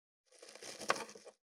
530厨房,台所,野菜切る,咀嚼音,ナイフ
効果音